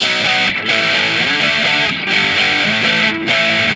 Guitar Licks 130BPM (7).wav